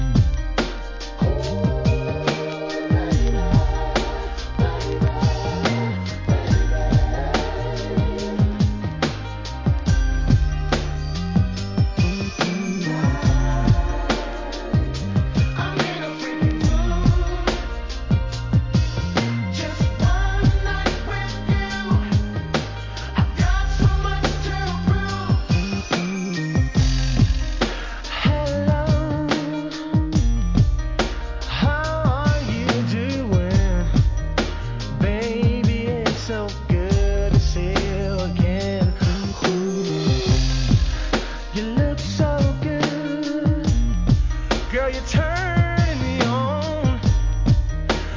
HIP HOP/R&B
実力のコーラスでムーディな良質作品が揃います！